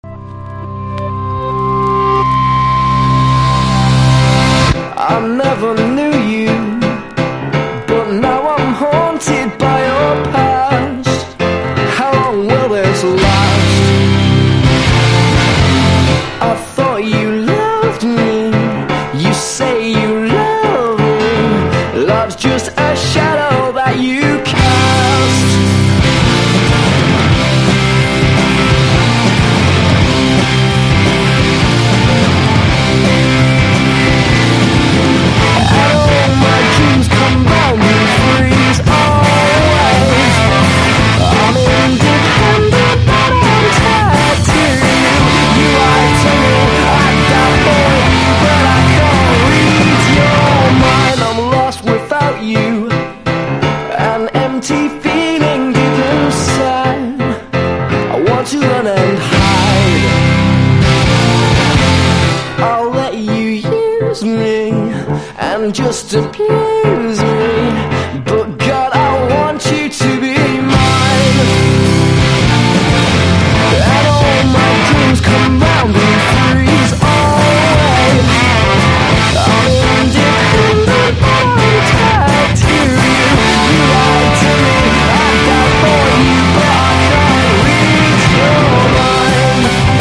# NEO ACOUSTIC / GUITAR POP